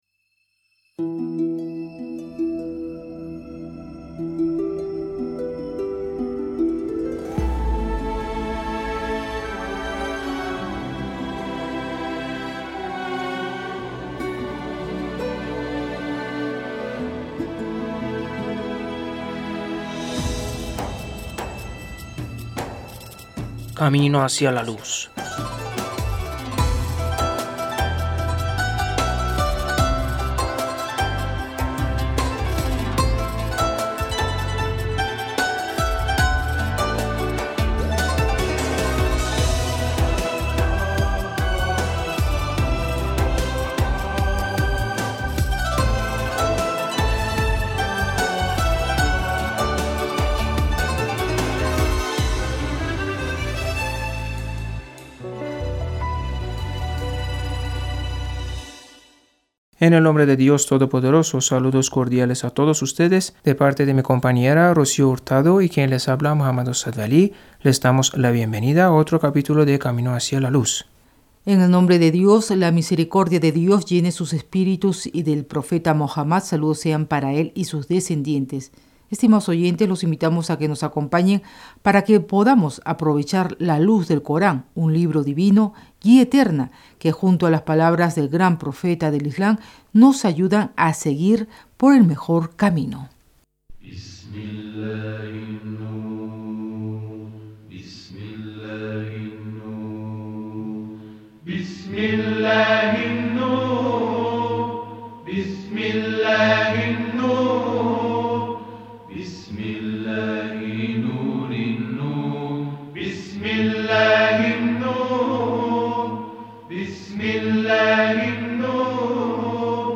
Comenzamos el programa escuchando las aleyas 123, 124, 125 y 126 de la sura de Los Puestos en Fila :